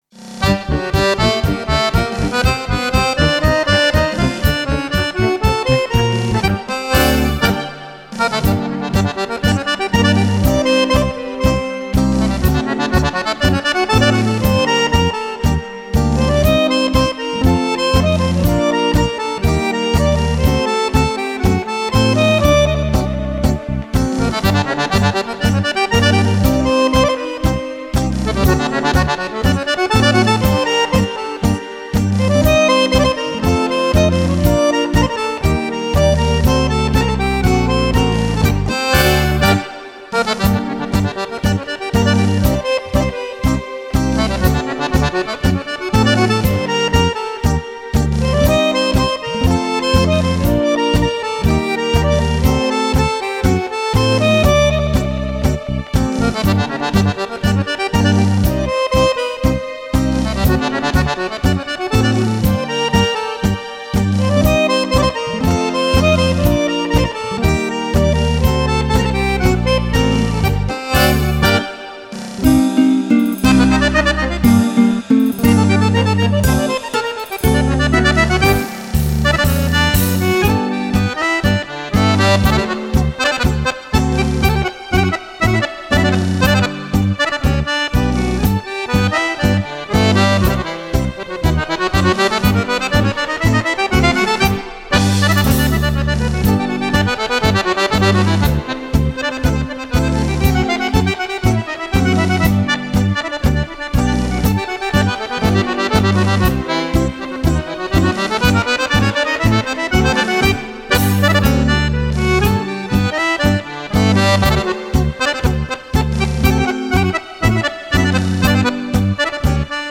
Tango
14 ballabili per fisarmonica solista
Registrato in Home Recording